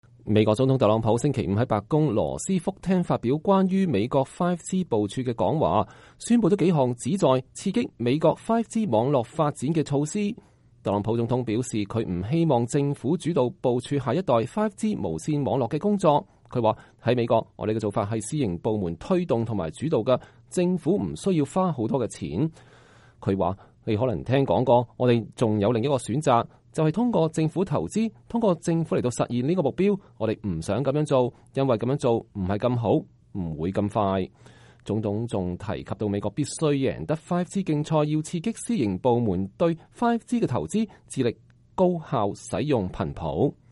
美國總統特朗普週五在白宮羅斯福廳發表關於美國5G部署的講話。(2019年4月12日)